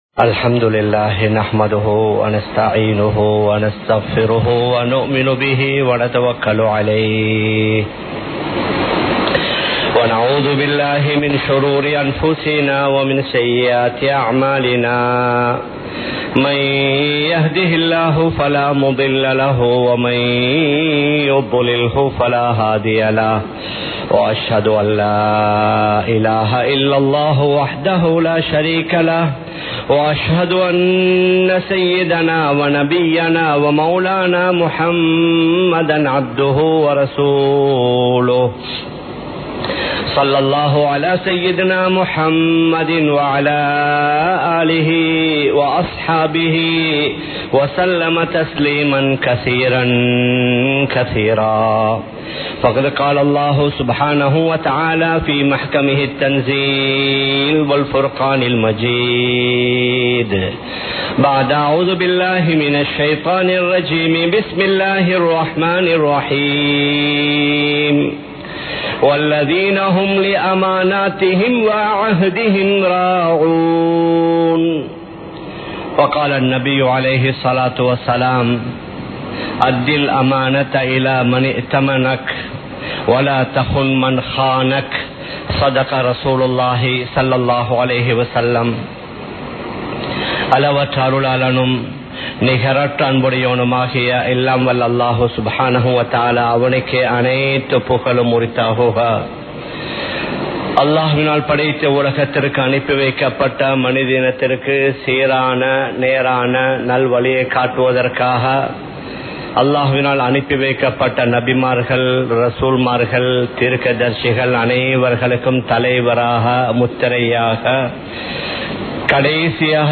அமானிதம் பேணுவோம் | Audio Bayans | All Ceylon Muslim Youth Community | Addalaichenai
Colombo 03, Kollupitty Jumua Masjith